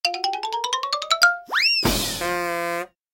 comedy_music_run_fall_and_crash